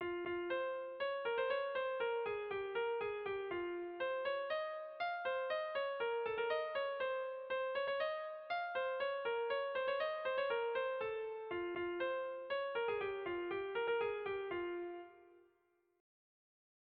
Bertso melodies - View details   To know more about this section
Sentimenduzkoa
A-B-B-A2